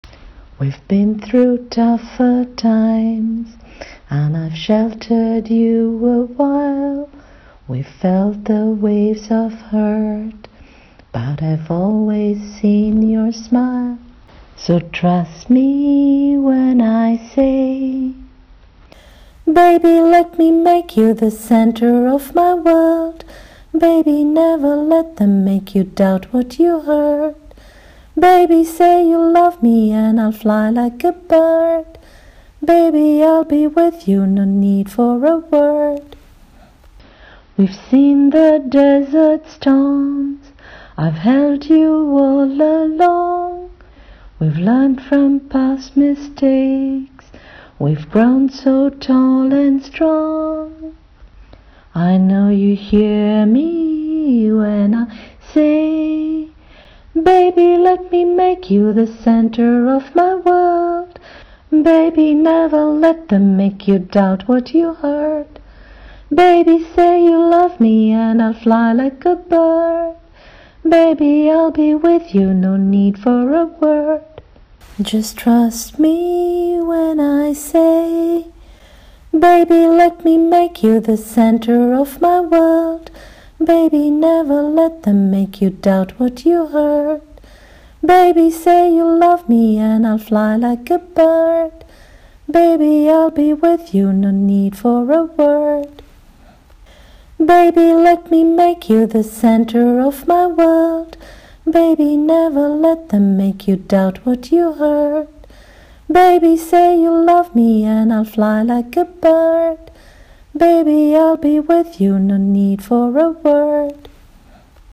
Singing of the lyrics: